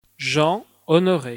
Fr-Jean_Honoré.ogg.mp3